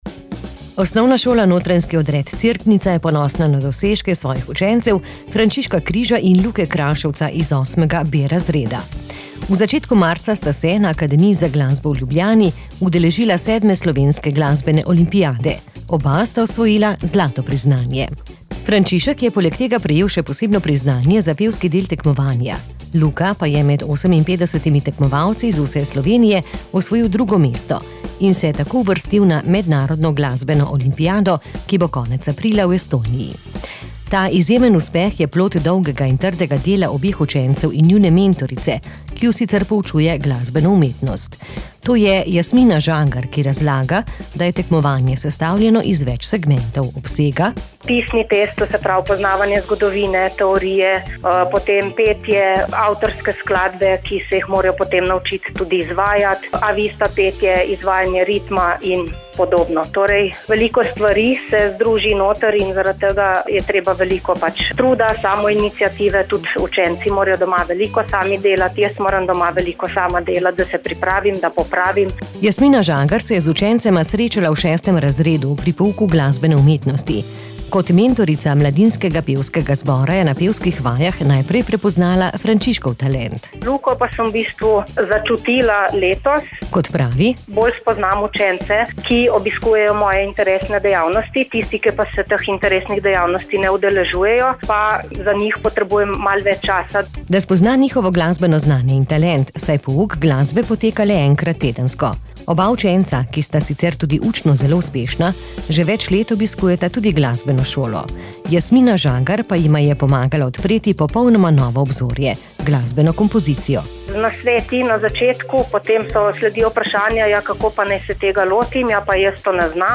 Povabili smo jo pred mikrofon.